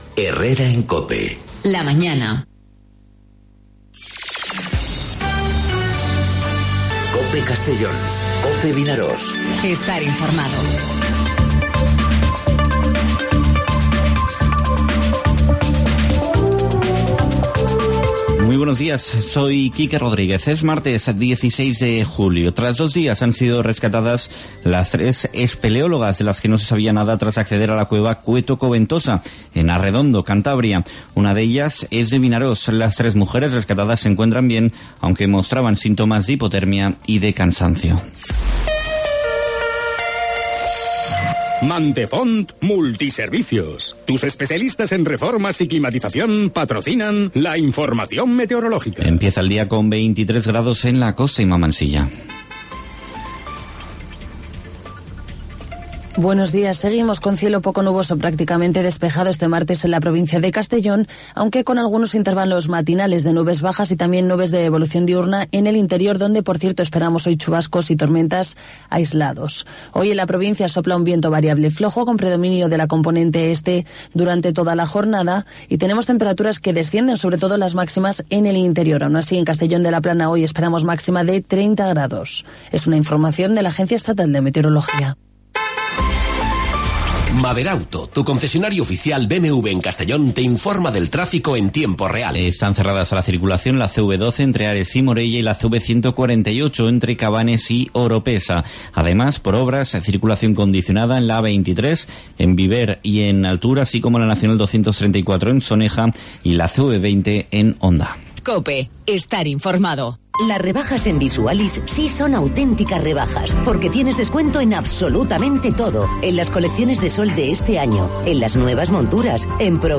Informativo 'Herrera en COPE' Castellón (16/07/2019)